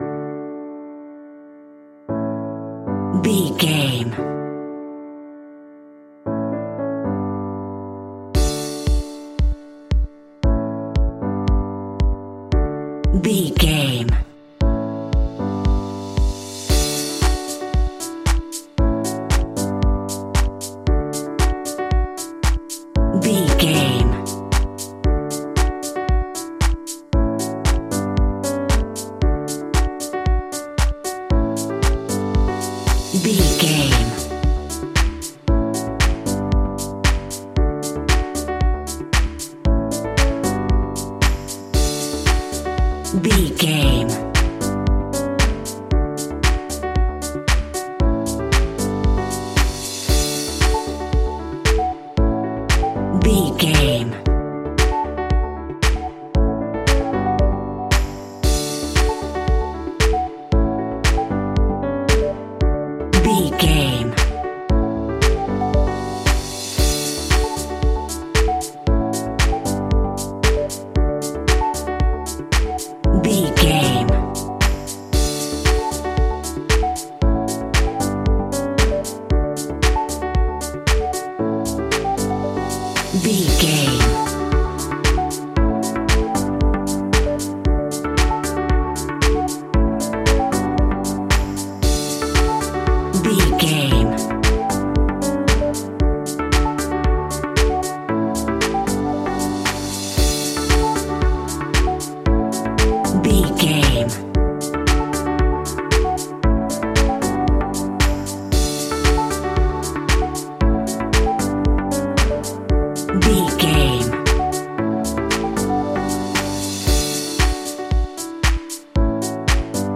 Ionian/Major
D
groovy
energetic
uplifting
futuristic
hypnotic
drum machine
synthesiser
piano
house
electro house
synth bass